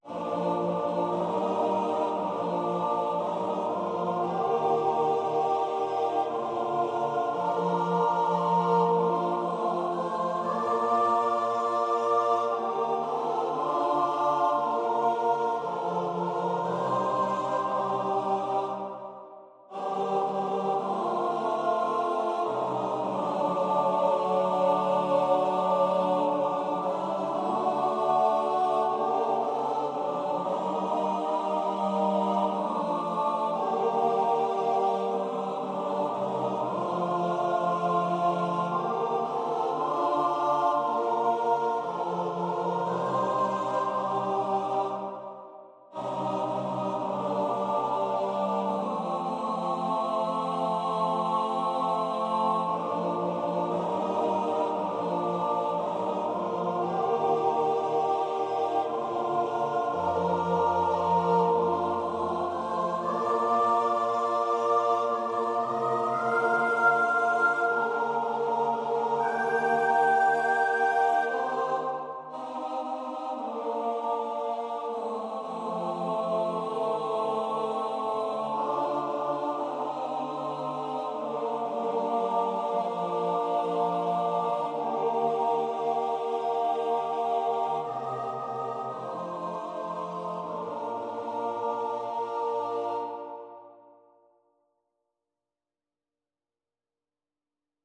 4-Part Chorales that aren’t Bach
Comments: The second phrase ends on the V/vi which creates an unusual type of deceptive cadence.
The tonicized plagal half cadence punctuates the IAC in the following phrase.